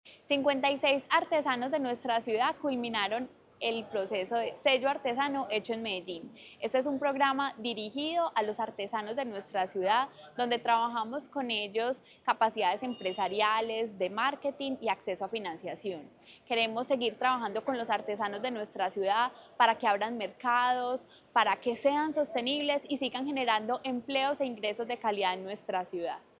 Palabras de María Fernanda Galeano, secretaria de Desarrollo Económico